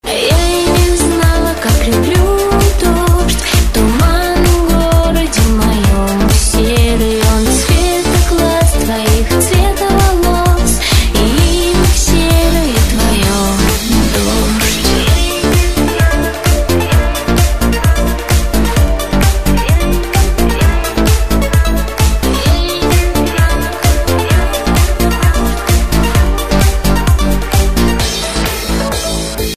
249 Категория: Клубные рингтоны Загрузил